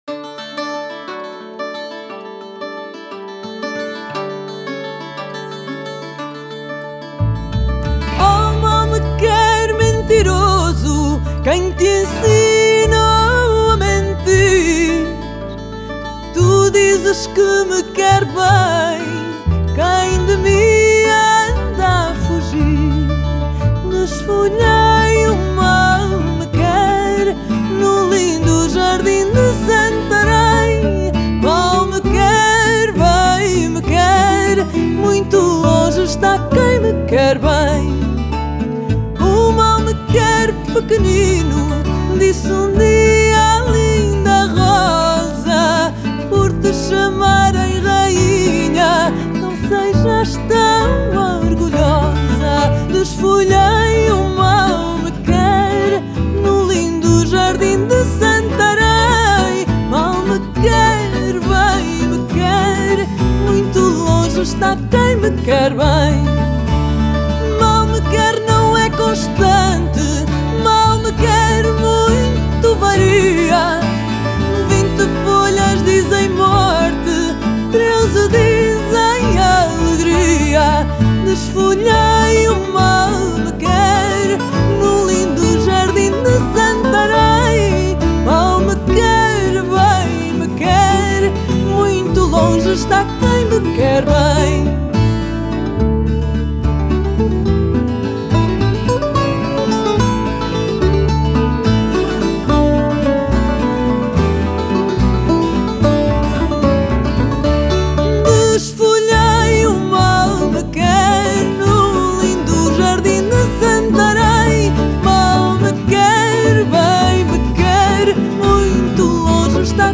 Genre: Fado